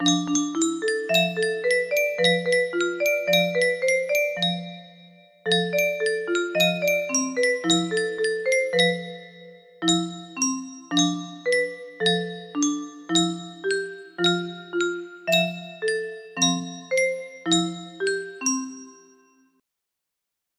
Clone of Unknown Artist - Untitled music box melody
Grand Illusions 30 (F scale)